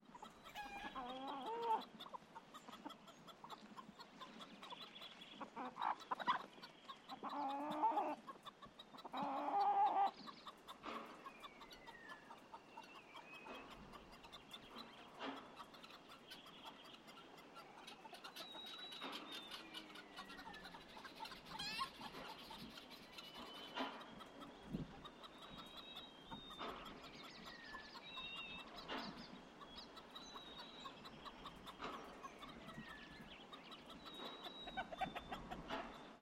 Атмосферные звуки деревенской фермы